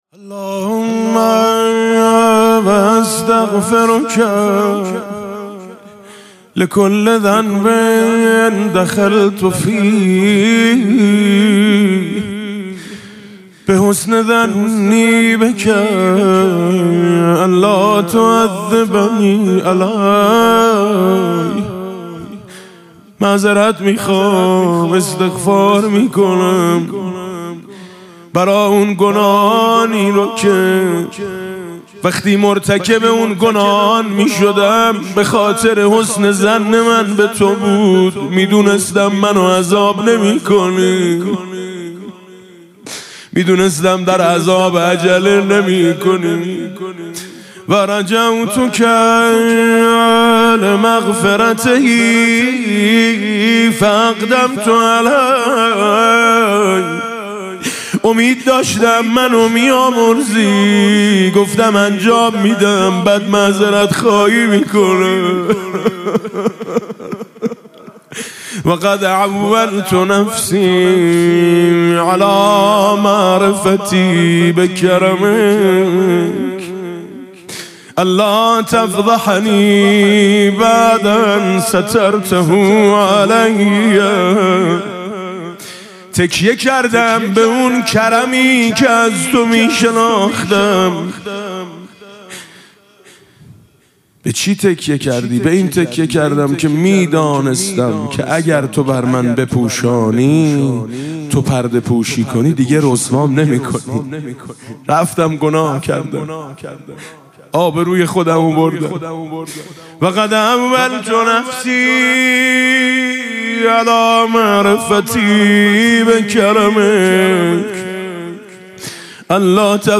قرائت استغفار ۷۰ بندی حضرت امام علی علیه السلام با نوای دلنشین حاج میثم مطیعی